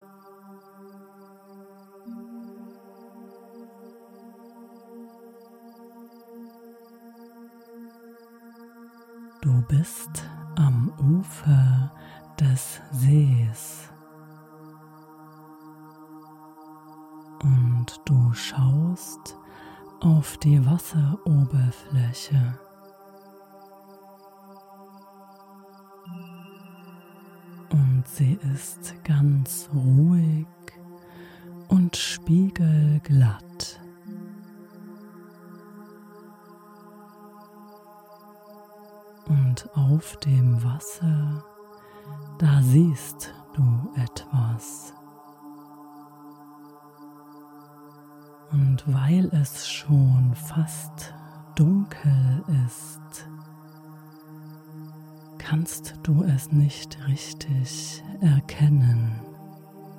Fantasiereise Abend am See als mp3-Download
Umgeben von Natur und beruhigenden Geräuschen kannst du den Tag hinter dir lassen und zur Ruhe kommen.